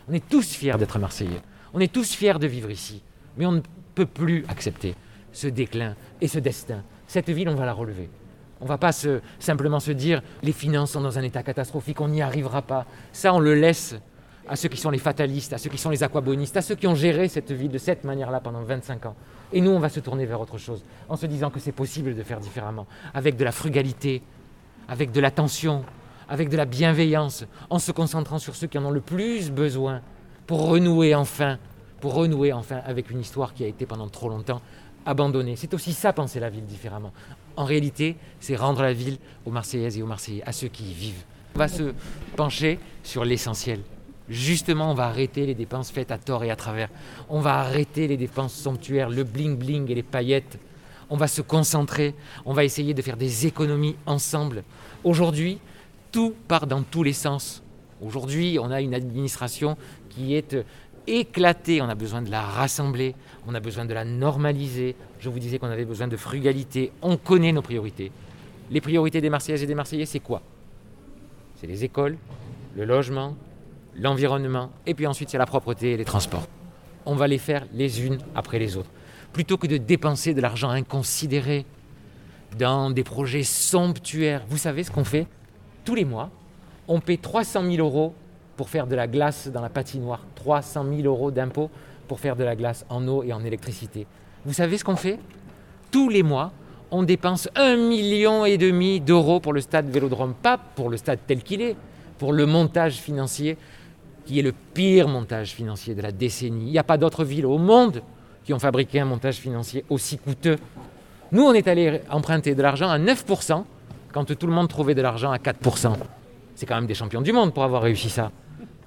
Entretien avec Benoît Payan